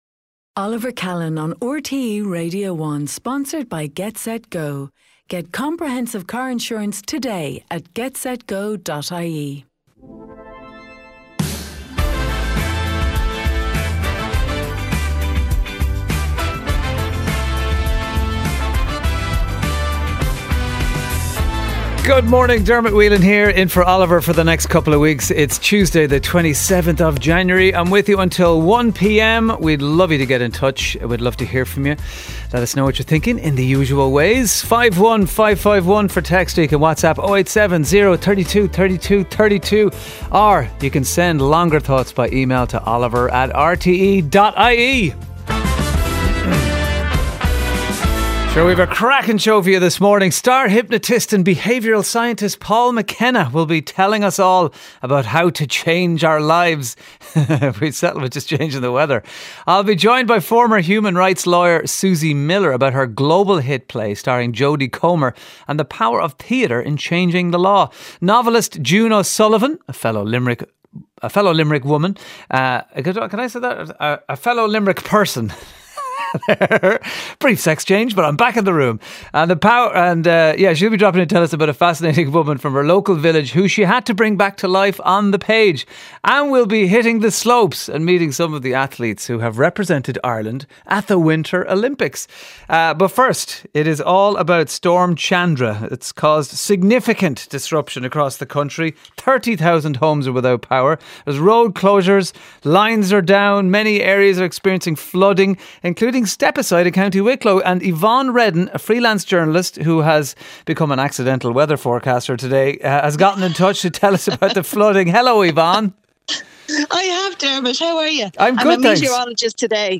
monologue on a Tuesday.